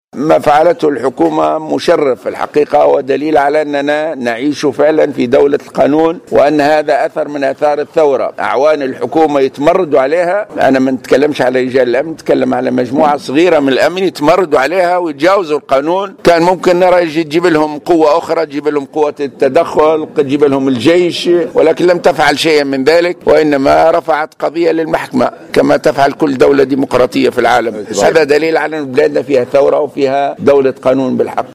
علق زعيم حركة النهضة راشد الغنوشي خلال حضوره في ندوة صحفية عقدت اليوم الأحد 28 فيفري 2016 على هامش المؤتمر الجهوي لحركة النهضة في ولاية صفاقس على التجاوزات التي ارتكبها عدد من الأمنيين المنتسبين للنّقابة الوطنيّة لقوّات الأمن الدّاخلي بعد اقتحامهم لحرمة مقر رئاسة الحكومة بالقصبة.
وقال الغنوشي في تصريح لمراسل الجوهرة أف أم في الجهة إن ما فعلته الحكومة مشرف ودليل على أننا نعيش في دولة القانون لأنها رفعت قضية للمحكمة ضد مجموعة من أعوان الأمن الذين تمردوا على القانون وتجاوزوه مثلما تفعل أي دولة ديمقراطية في العالم على حد تعبيره.